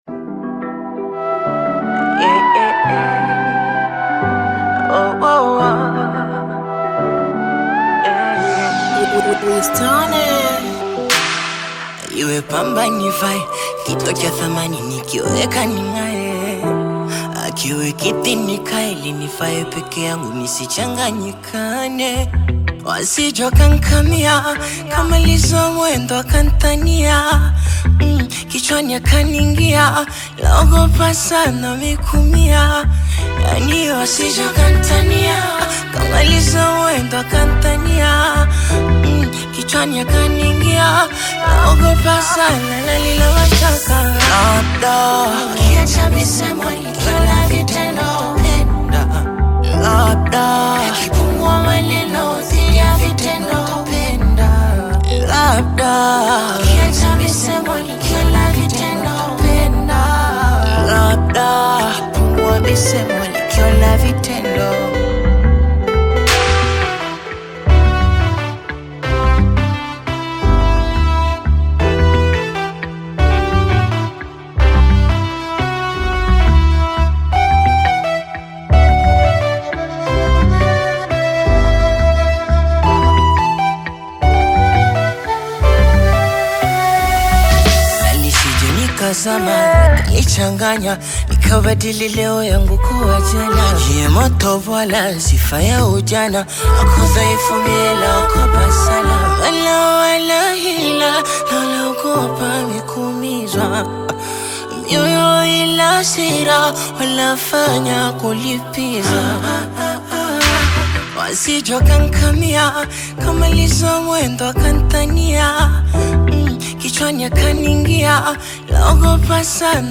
Tanzanian upcoming singer and songwriter
love song
African Music